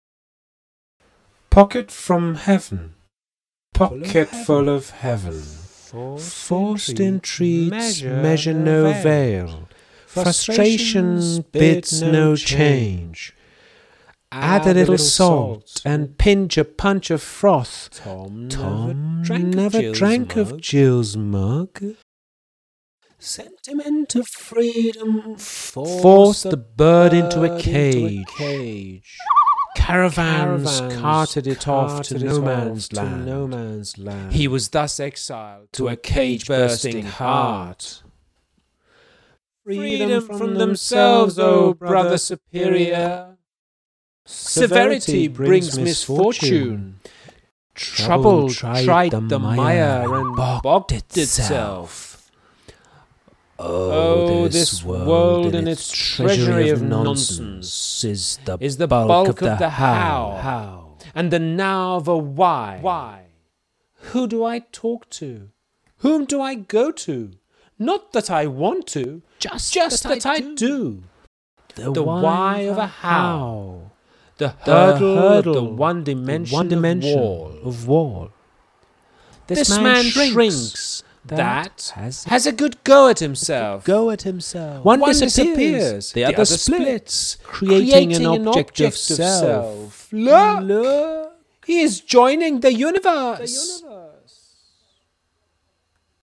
برای شنیدن شعر با صدای شاعر